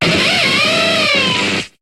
Cri de Luxray dans Pokémon HOME.